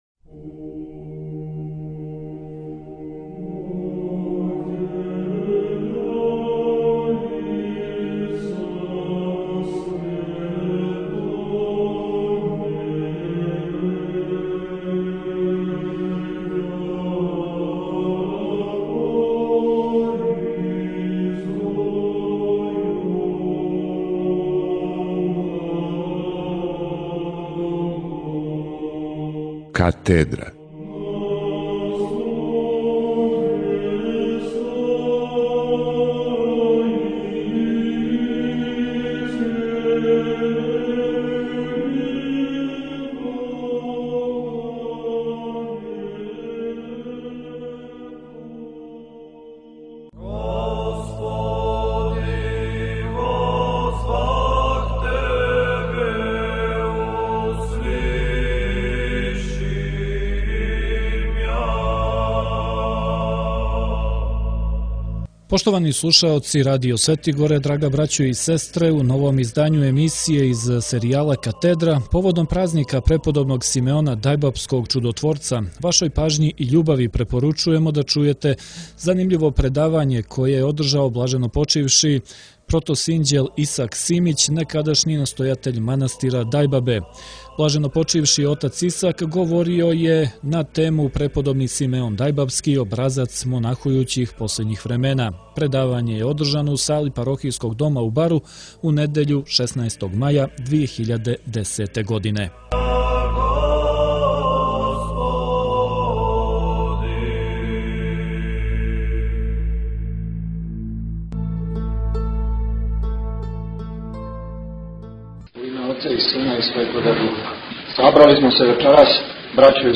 Предавање је одржано у сали Парохијског дома у Бару у недељу 16. маја 2010. године.